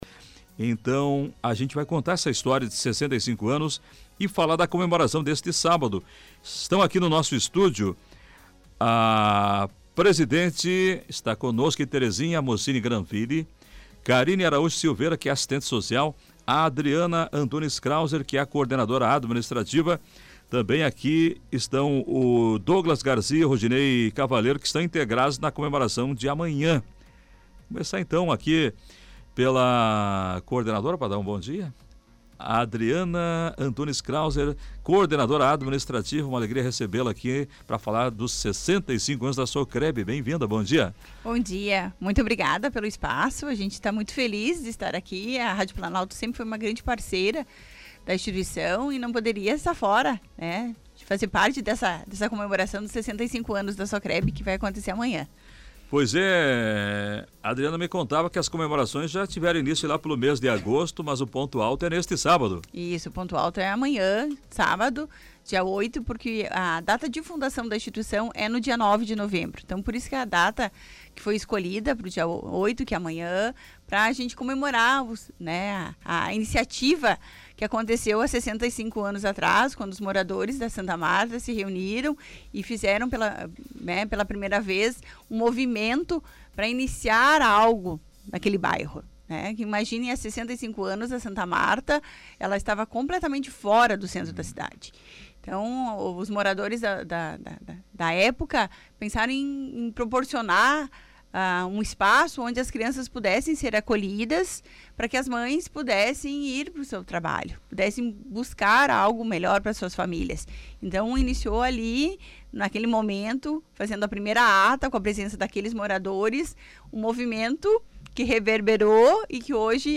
Para destacar a preparação, o programa Comando Popular, da Rádio Planalto News (92.1) recebeu a direção da entidade.